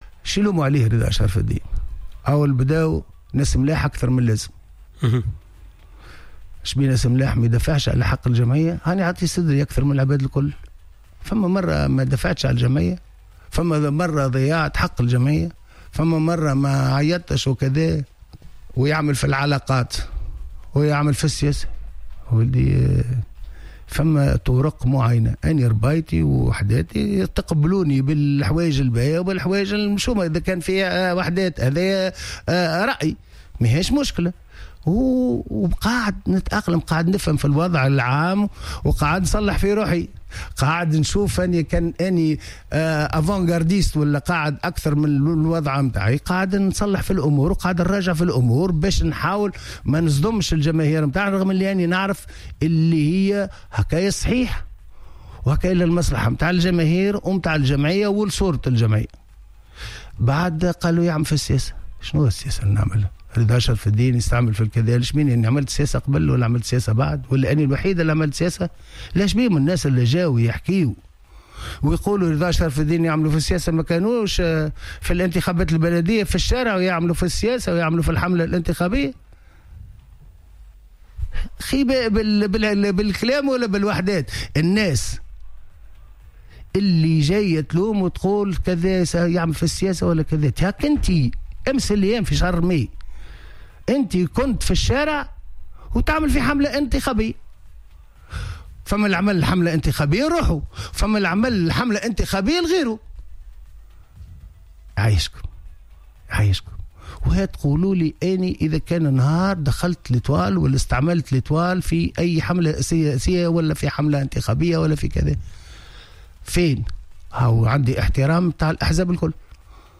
أفاد رئيس النجم الساحلي رضا شرف الدين ضيف حصة "Planète Sport" أن مهمته كرئيس للنجم الساحلي تتمثل في قيادة الفريق و الدفاع على مصالحه مضيفا أنه حريص على تحييد النجم عن الحسابات السياسية.